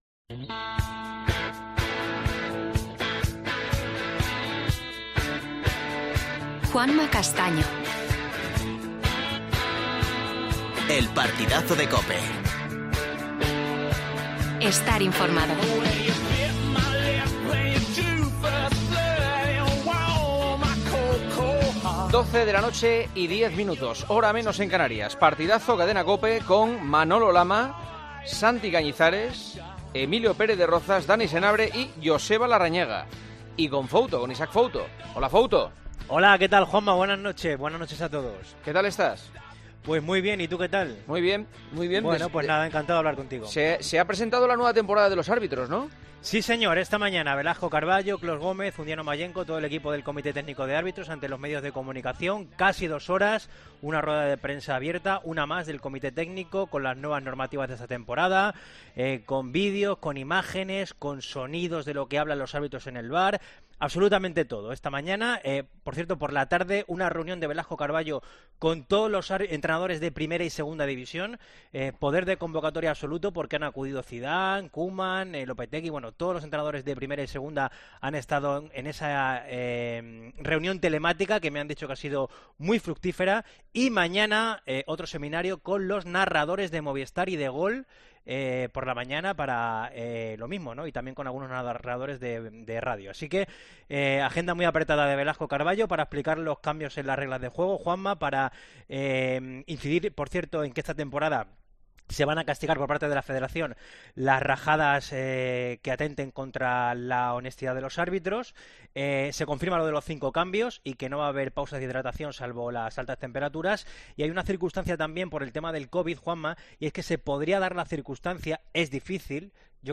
AUDIO: Seguimos con el Tiempo de Opinión. Entrevista a Carlos Velasco Carballo, presidente del CTA.